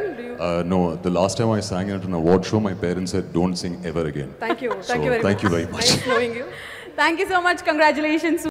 Text-to-Speech
speech generation
voice-cloning